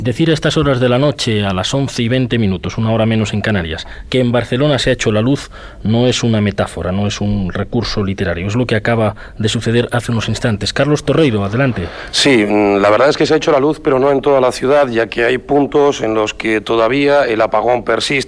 Indicatiu del programa, informació d'una apagada elèctrica general a la ciutat de Barcelona.
Informació des de la central de la Guàrdia Urbana de Barcelona.
Informatiu